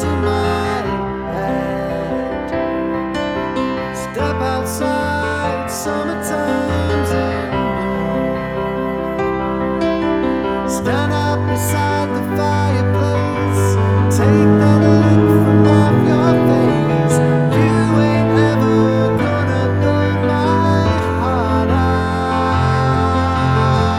Down 2 Male Key